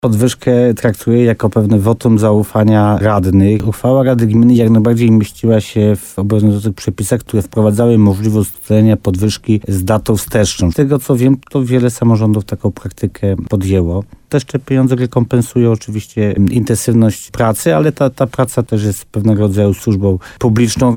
Paweł Ptaszek w programie Słowo za Słowo w RDN Nowy Sącz mówił, że jest to dla niego dowód uznania jego pracy.